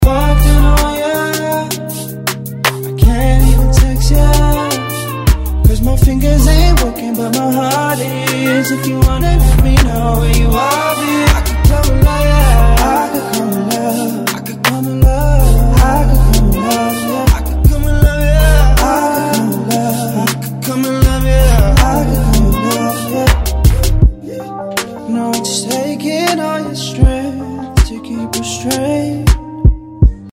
Категория: Спокойные рингтоны